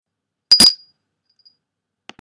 The sound of the bell tells you where a word/expression is missing .